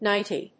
発音記号
• / nάɪṭi(米国英語)